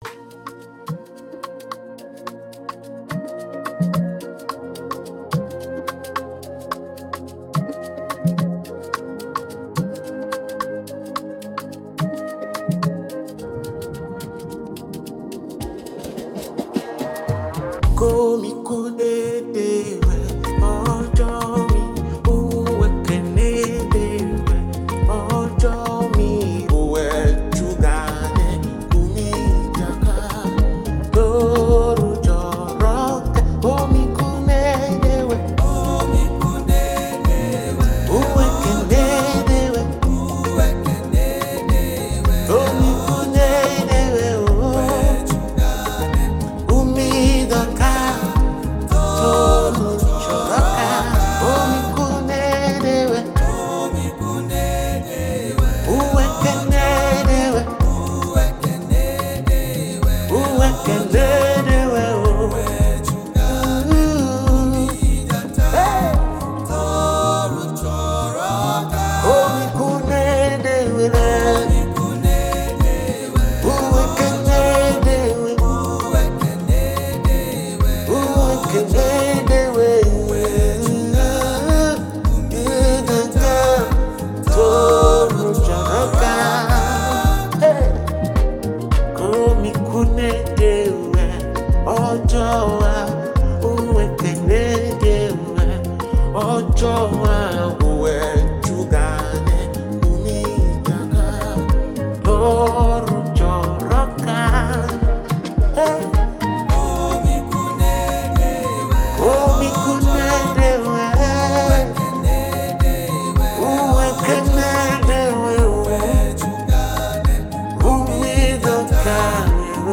heartfelt worship song
With a soul-lifting sound and spirit-filled delivery